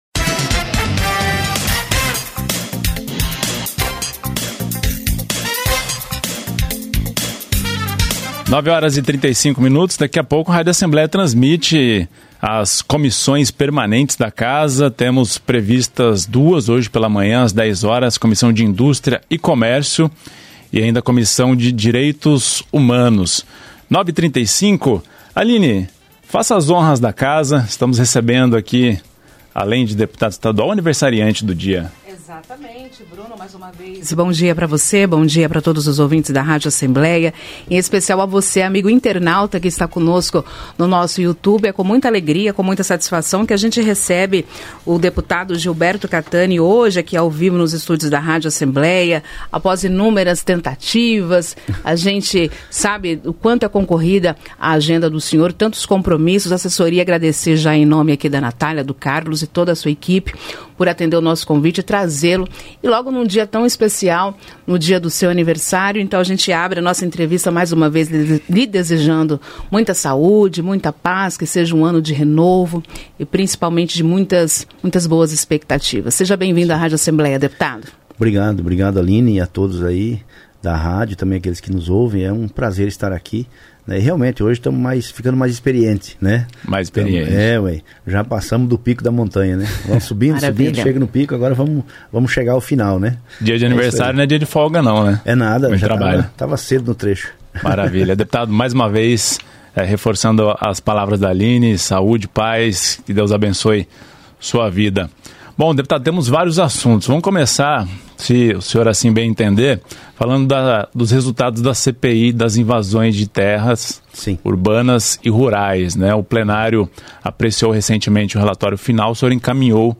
Programa Painel Entrevista: Deputado Gilberto Cattani